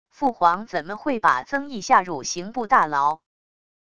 父皇怎么会把曾毅下入刑部大牢wav音频生成系统WAV Audio Player